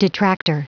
Prononciation du mot detractor en anglais (fichier audio)
Prononciation du mot : detractor